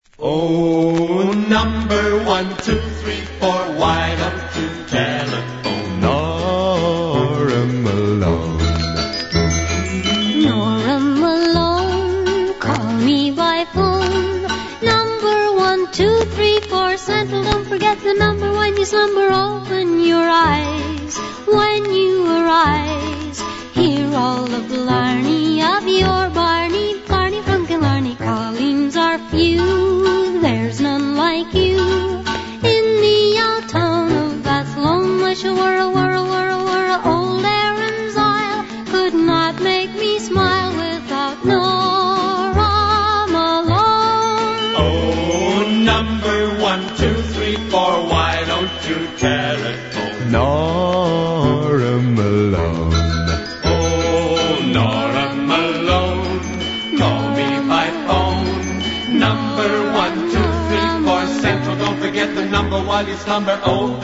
(vinyl)